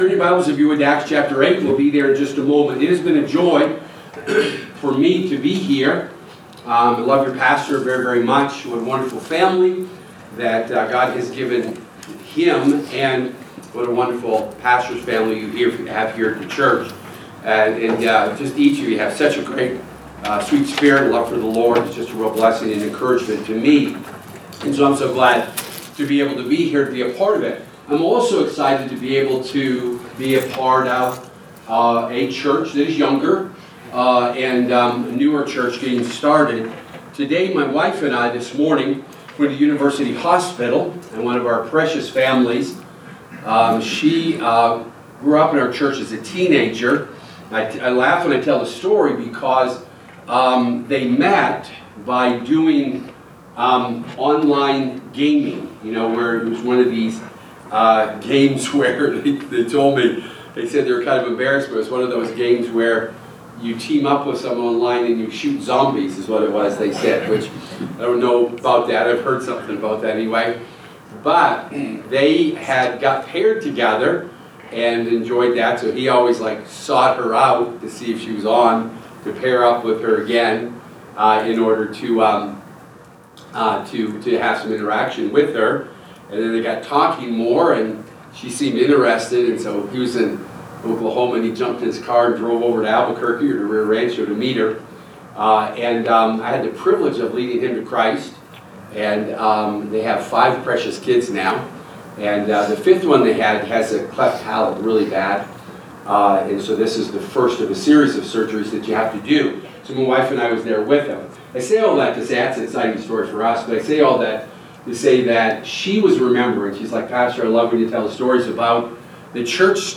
Winter Revival 2026 – Building Through Soulwinning